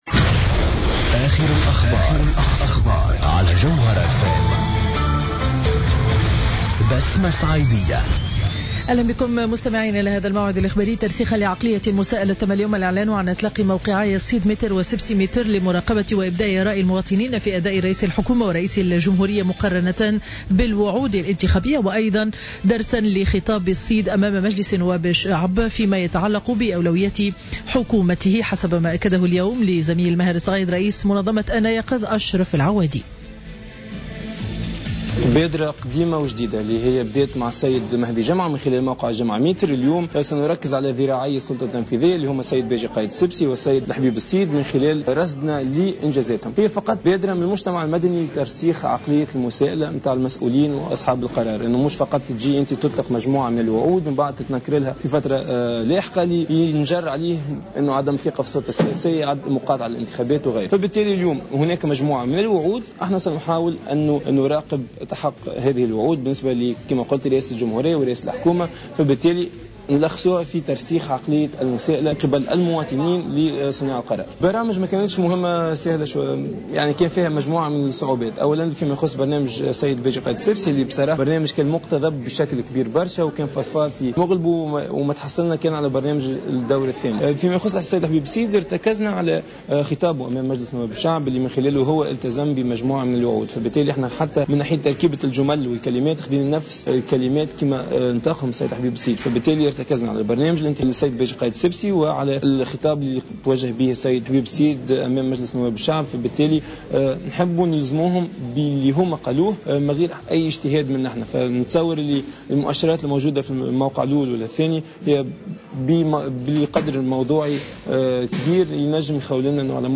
نشرة أخبار منتصف النهار ليوم الاربعاء 29 أفريل 2015